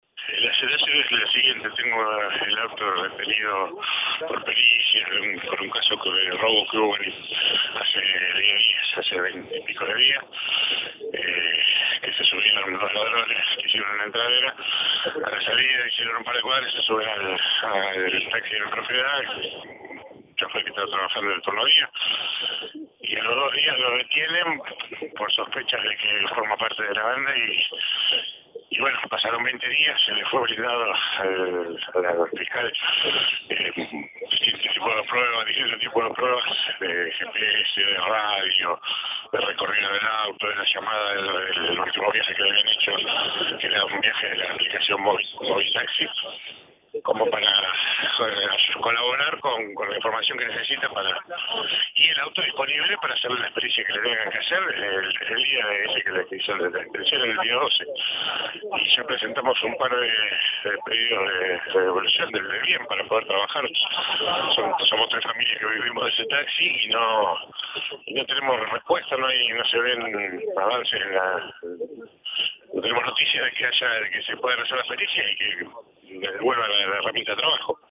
Audio. Reclamo de taxistas en el Centro de Justicia Penal de Rosario.